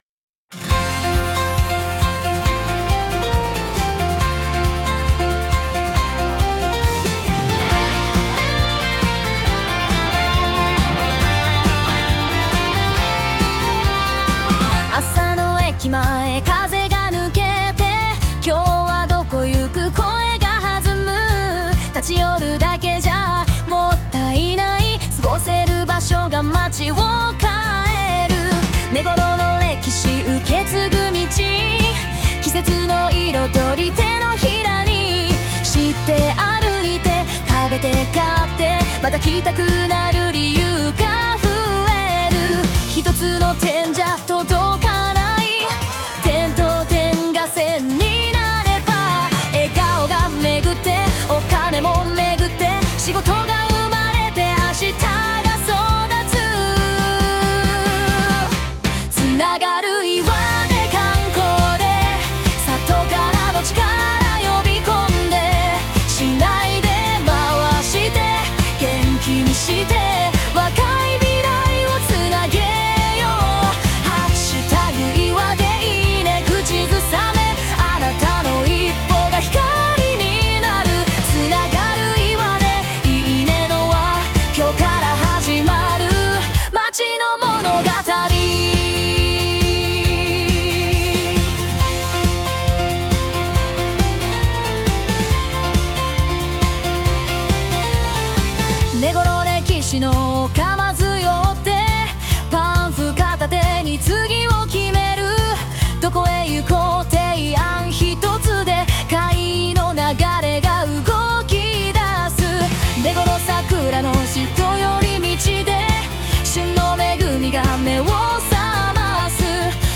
ソング版